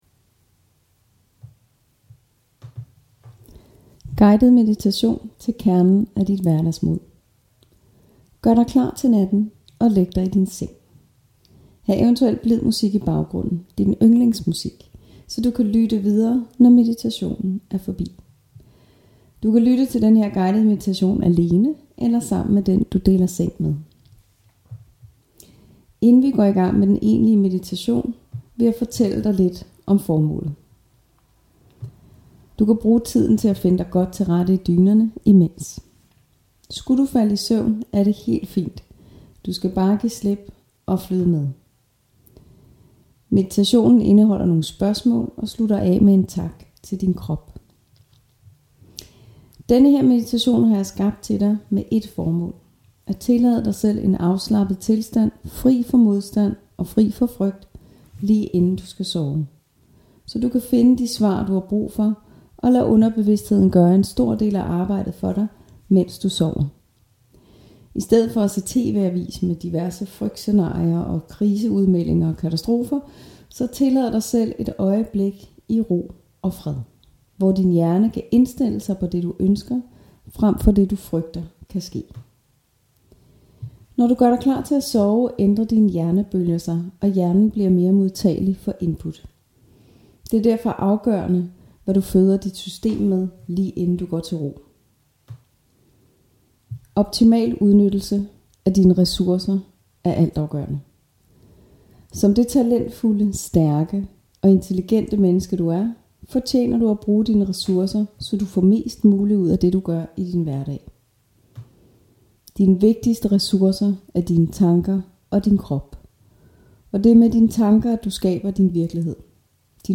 Guidet Meditation - Hverdagsmod